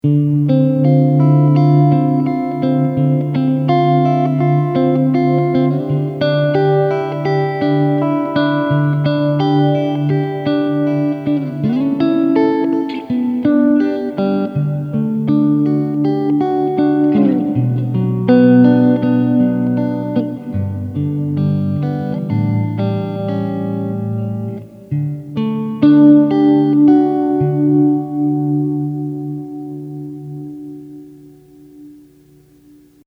I just recorded in an open room with no filtering. BTW, I used a Strat with a prototype Aracom RoxBox 18 Watt Amp with a Jensen 1 X10 speaker.
Hall
rv7_hall.mp3